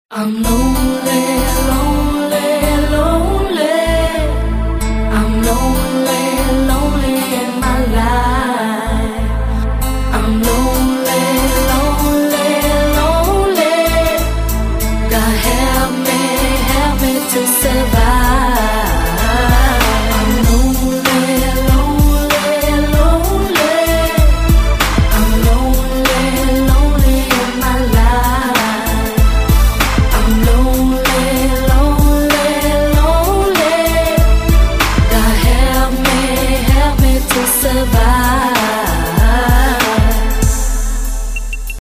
Категория: Спокойные